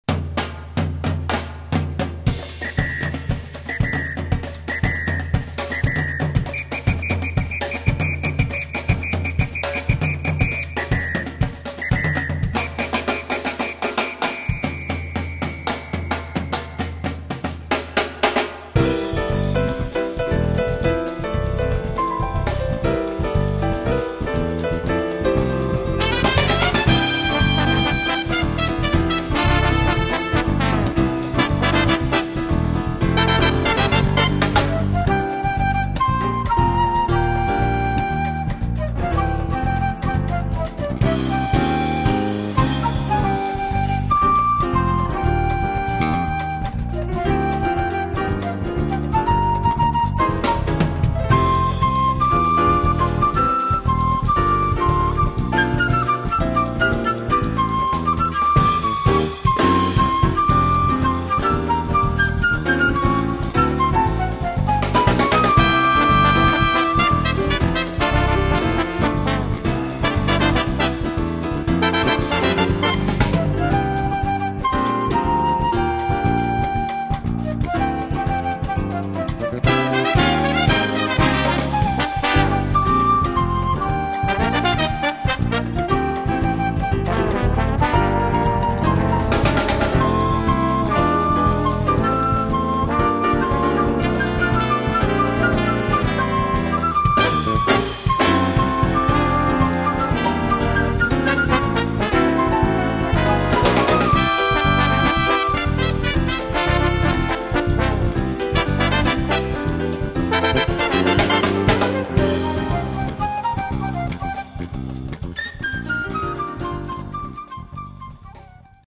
Category: combo
Style: samba
Solos: open